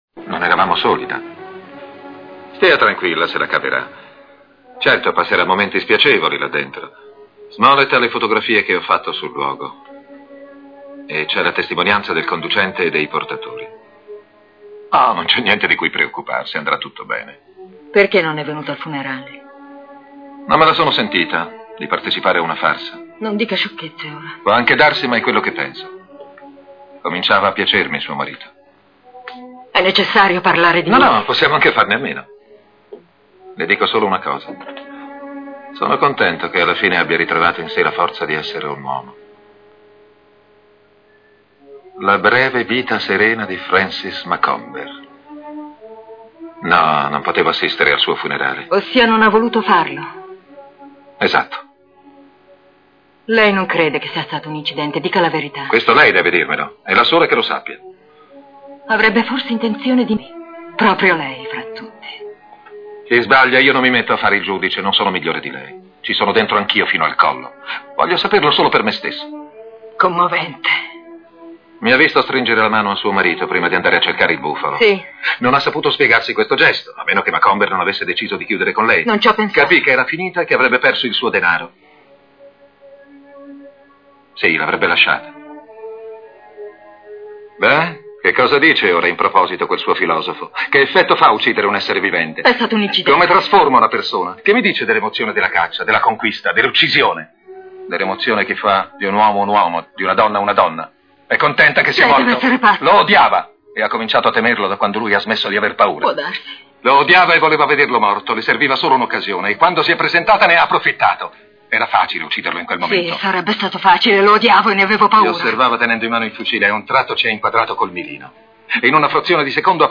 dal film "Passione selvaggia", in cui doppia Gregory Peck.